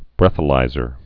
(brĕthə-līzər)